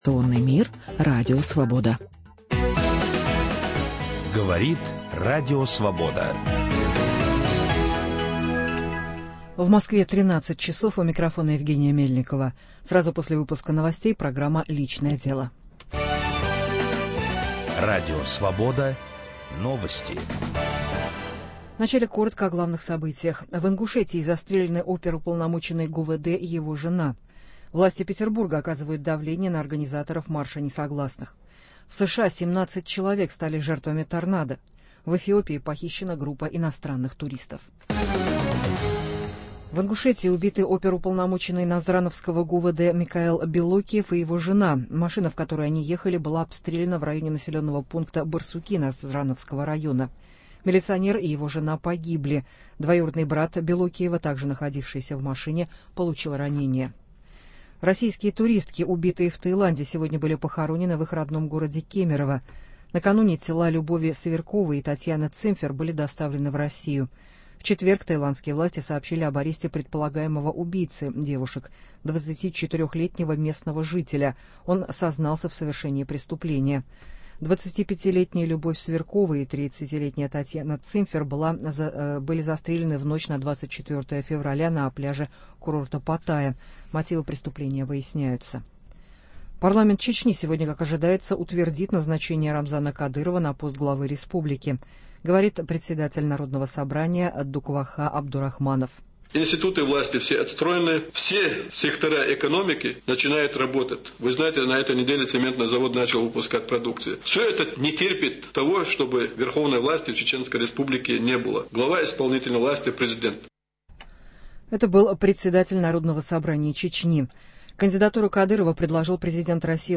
2 марта в прямом эфире мы будем говорить о типе мужчин, число которых, по оценкам социологов, все увеличивается, - о "маменькиных сынках". Как суметь остаться любящим и благодарным сыном и не попасть под тотальную материнскую опеку?